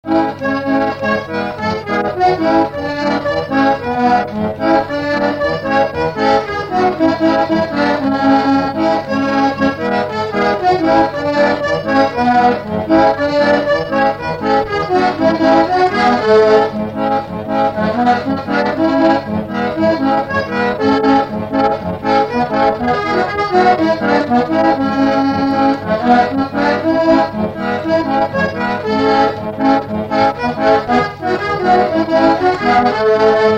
Instrumental
danse : séga
Pièce musicale inédite